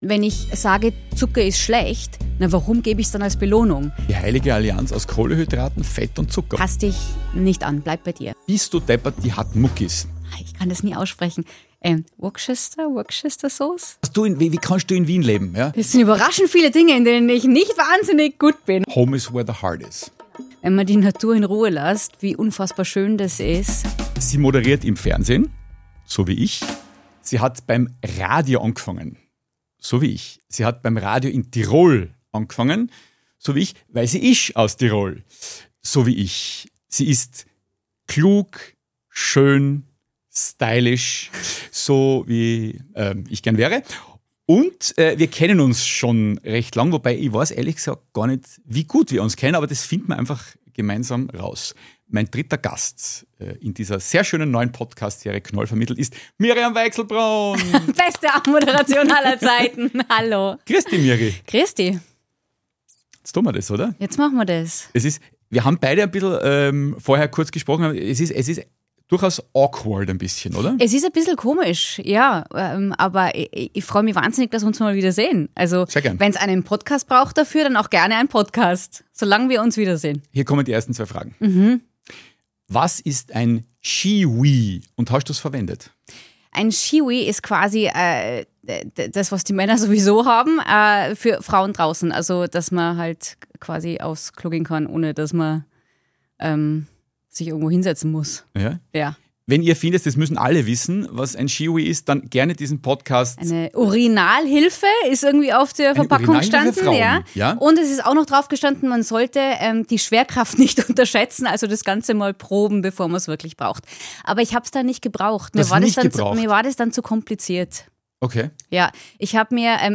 Ein Gespräch, das klingt wie ein guter Abend mit Freunden.